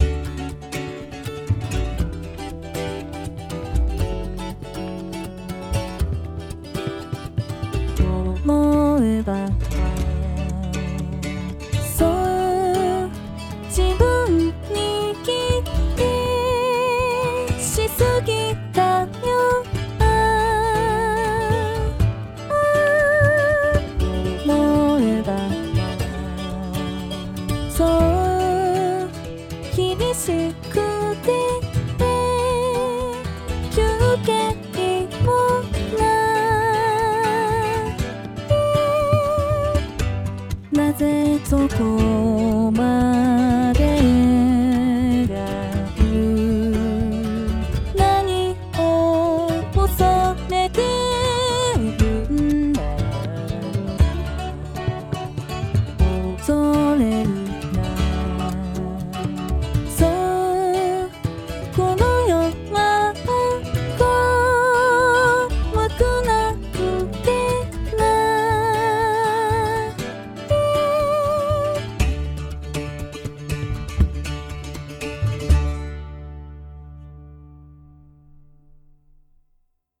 ※Band-in-a-Boxによる自動作曲